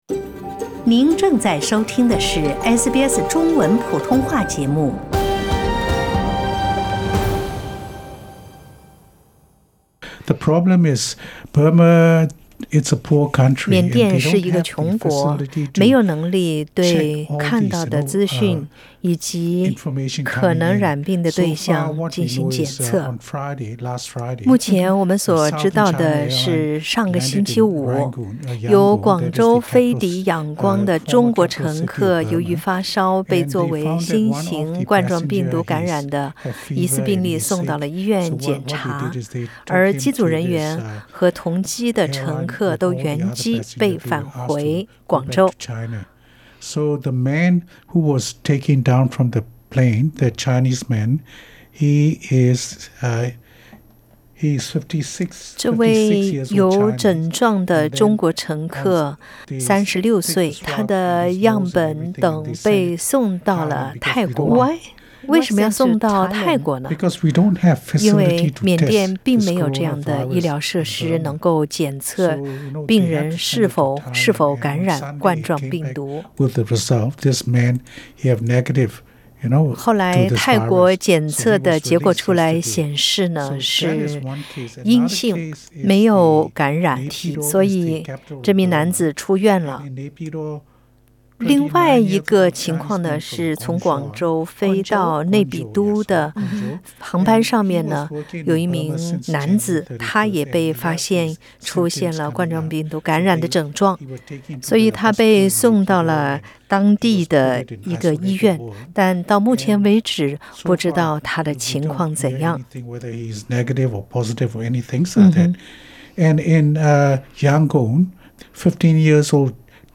鄰國爆髮新冠病毒疫情，緬甸人是否擔心？澳洲的緬甸社區有著怎樣的反應？點擊上方圖片收聽寀訪報道。